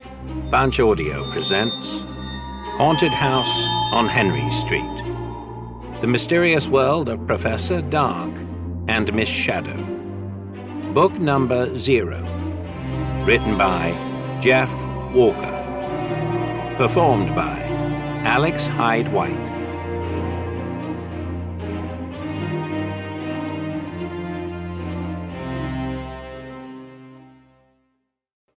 Haunted House on Henry Street (EN) audiokniha
Ukázka z knihy